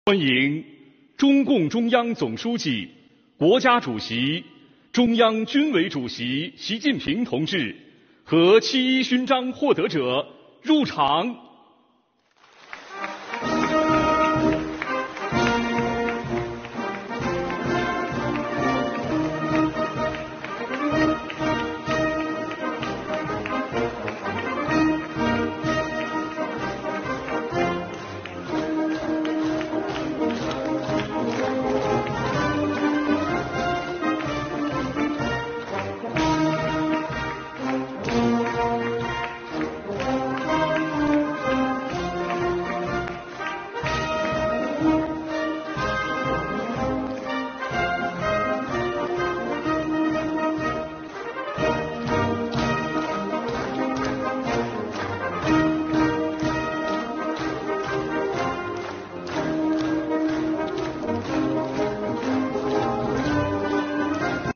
在雄壮的《忠诚赞歌》乐曲声中，习近平为“七一勋章”获得者颁授勋章。全场响起热烈的掌声。